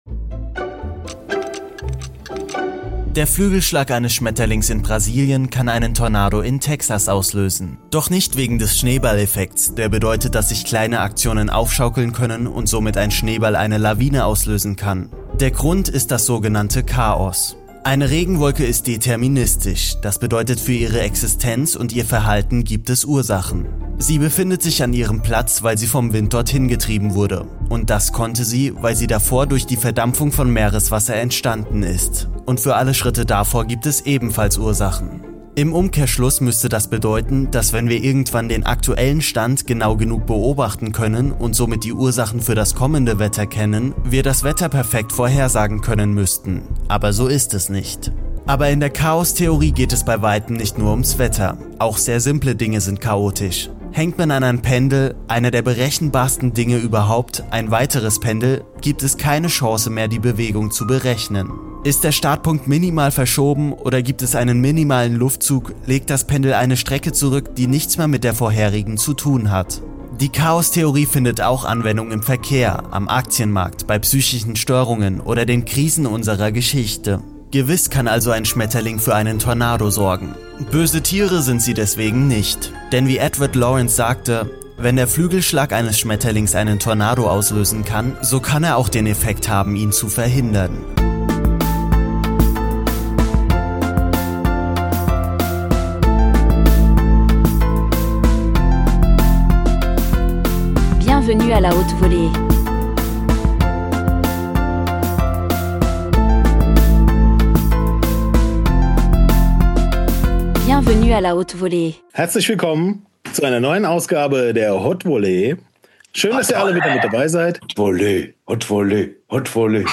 Beschreibung vor 4 Monaten Folge 122 – Freestyle-Fail & Zeitreise Deluxe In dieser Folge wird’s musikalisch fragwürdig und philosophisch hochwertig – die perfekte Hautevolee-Mischung also.